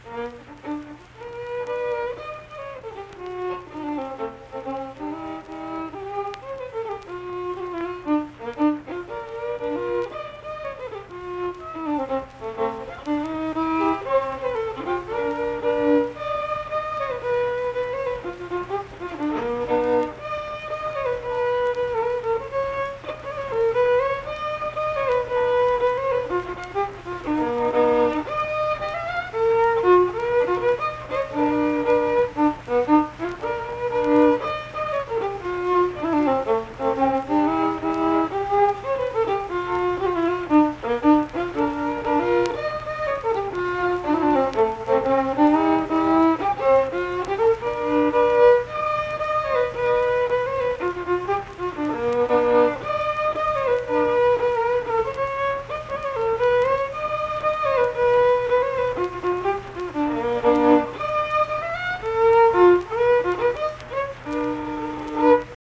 Unaccompanied vocal and fiddle music
Instrumental Music
Fiddle
Saint Marys (W. Va.), Pleasants County (W. Va.)